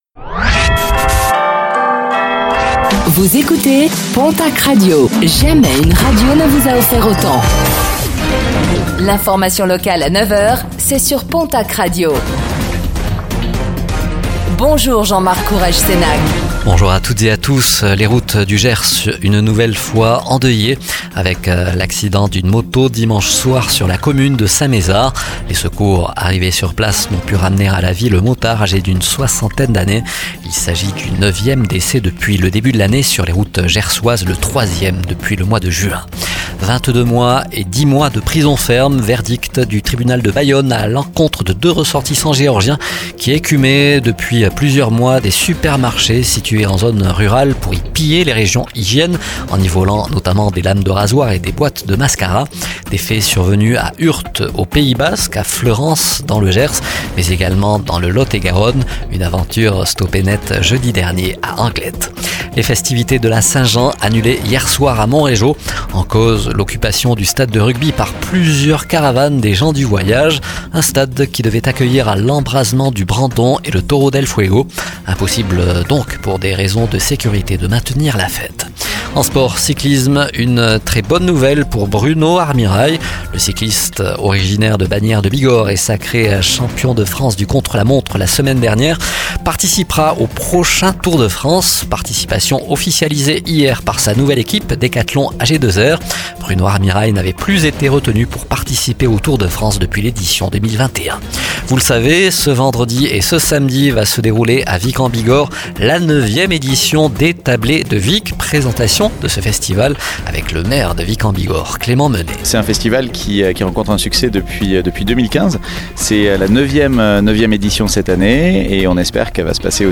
Réécoutez le flash d'information locale de ce mardi 25 juin 2024